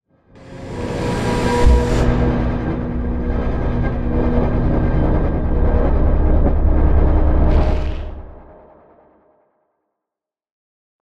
conjuration-magic-sign-rune-complete.ogg